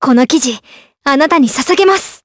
Leo-Vox_Skill4_jp.wav